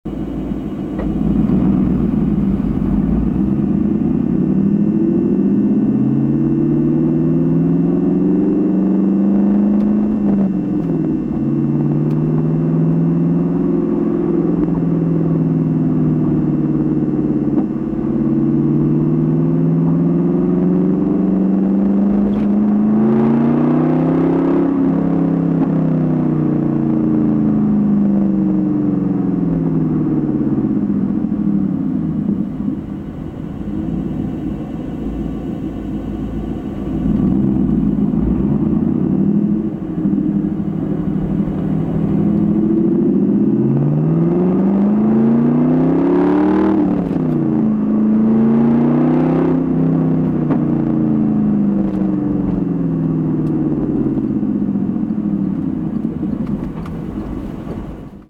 The result is that the factory muffler contributes to the mellowing of the sound, adding a bit of low frequency response to what would have been a raspy exhaust note.
Nonetheless, the rest of the RPM range above 1500 sounds heavenly whether on the throttle or off, as shown in the following sound files:
: taped inside the car while driving